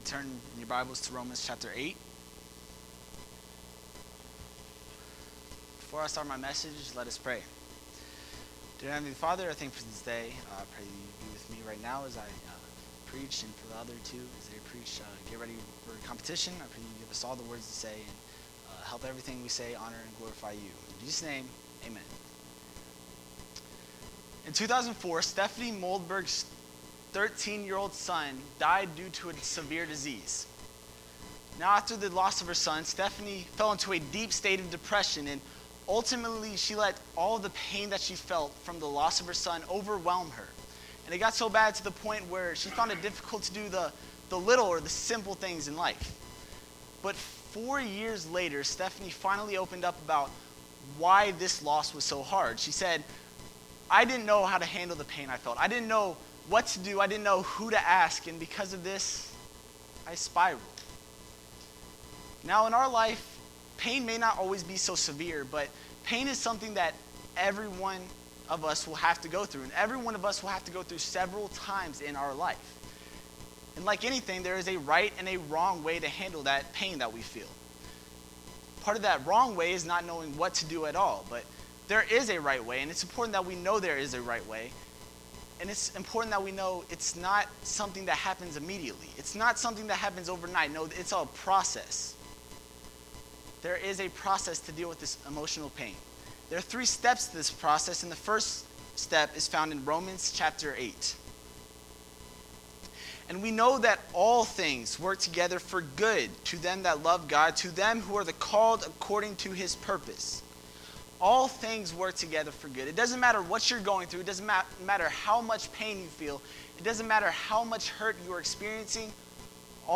March 4, 2020 (Wednesday Prayer Service)